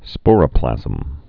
(spôrə-plăzəm)